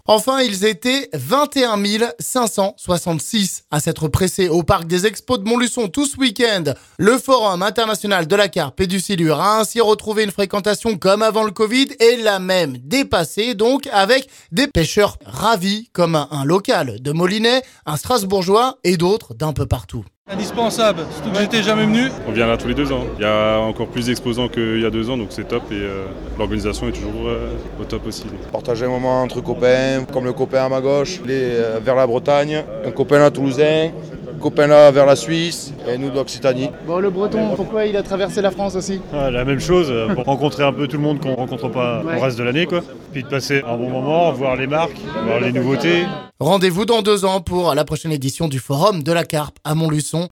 Témoignages ici...